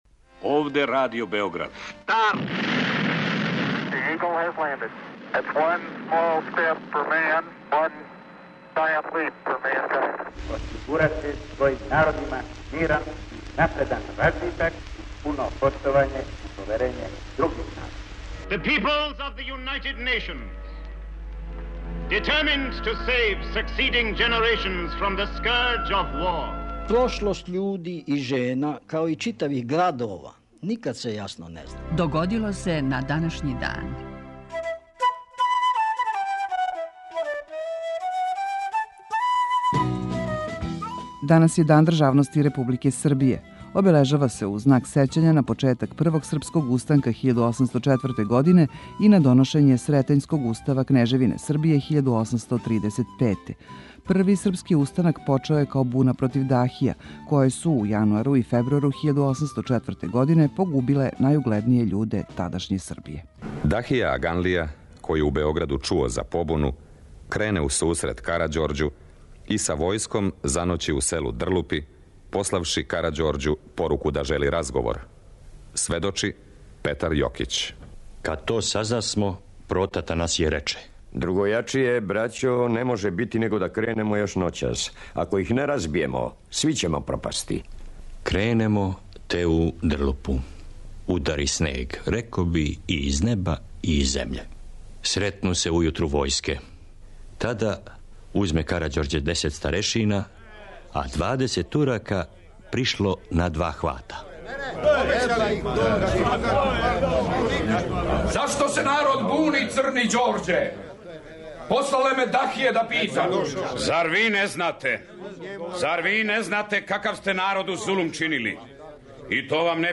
Емисија Догодило се на данашњи дан, једна од најстаријих емисија Радио Београда свакодневни је подсетник на људе и догађаје из наше и светске историје. У петотоминутном прегледу, враћамо се у прошлост и слушамо гласове људи из других епоха.